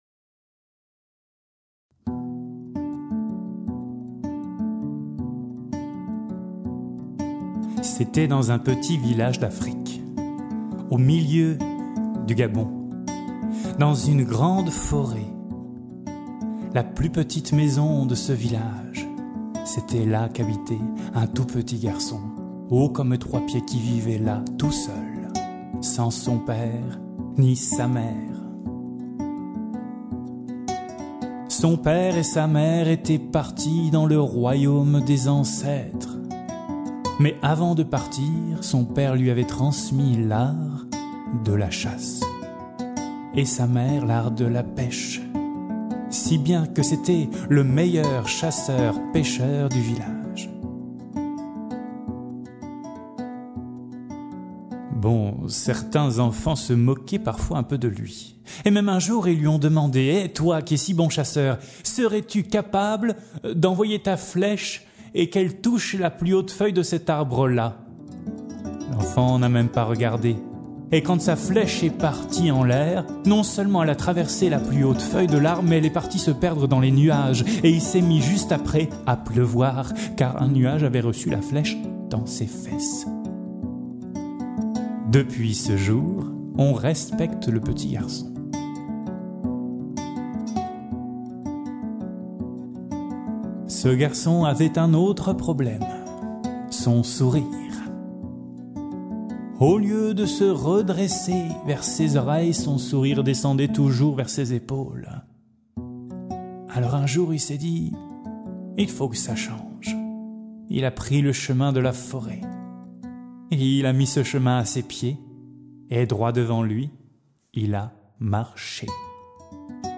conte africain